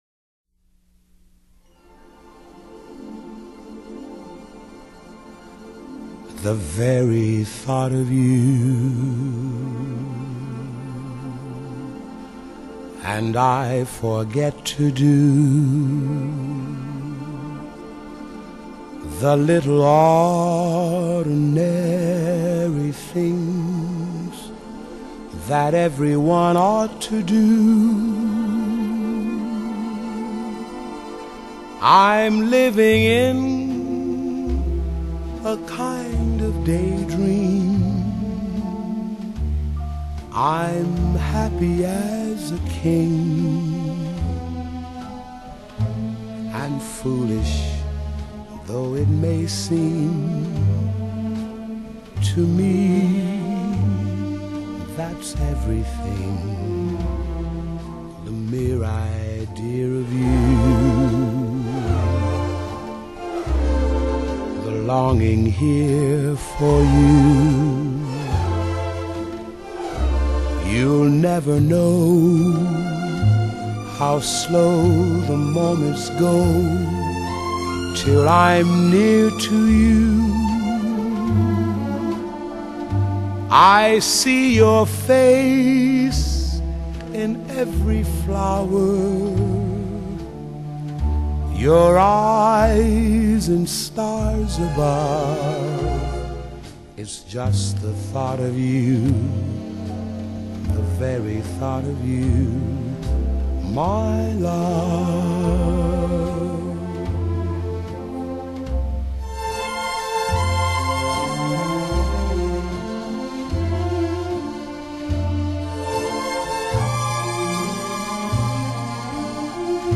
音樂類型：爵士樂
+ 352 MB (CD) | Vocal